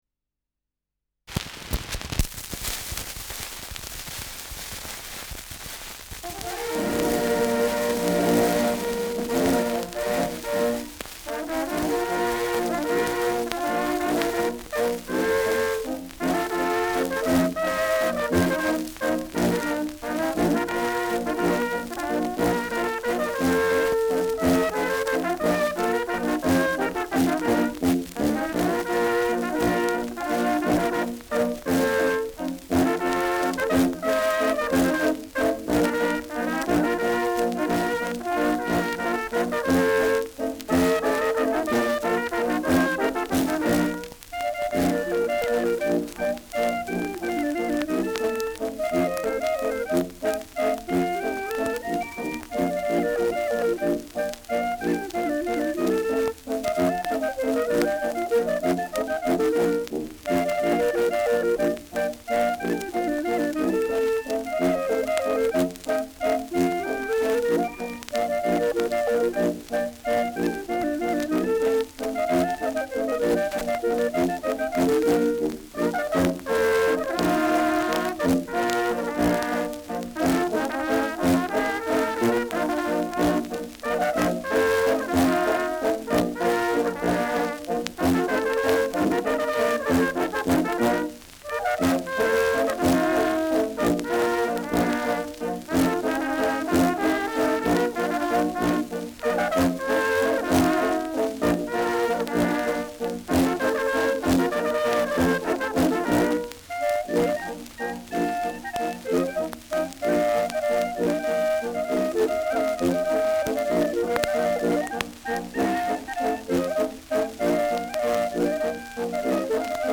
Schellackplatte
Stärker Grundrauschen : Zu Beginn stark verrauscht : Durchgehend leichtes bis stärkeres Knacken